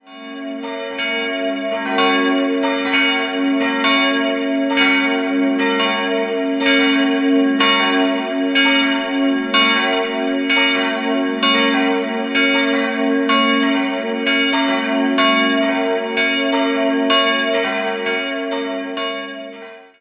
Der Turm wiederum wurde erst 1890 errichtet. 3-stimmiges Geläut: ges'(+)-b'-des'' Die große Glocke wurde 1892 von den Gebrüdern Klaus in Heidingsfeld gegossen, die beiden anderen 1977 von der Heidelberger Glockengießerei.